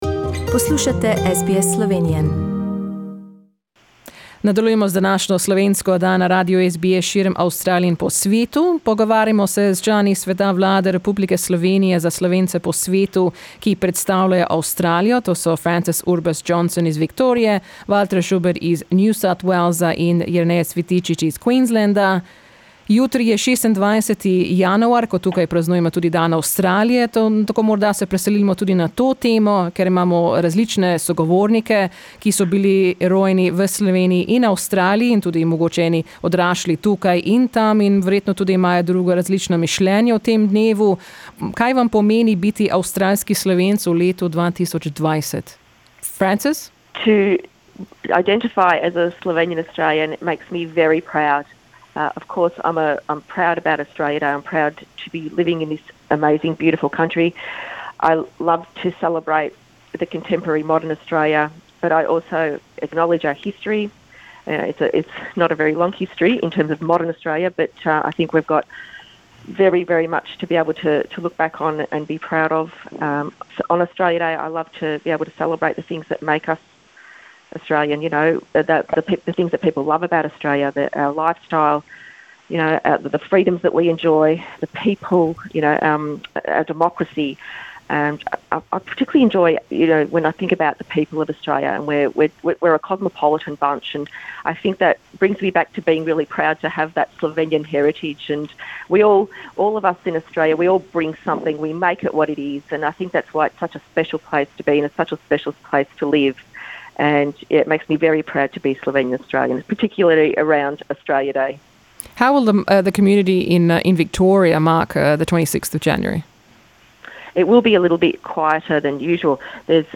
Jutri je 26. januar, ko praznujemo Dan Avstralije. Vprašali smo člane sveta vlade Republike Slovenije za Slovence po svetu, ki predstavljajo Avstralijo, kaj jim pomeni ta dan in kako bodo praznovali.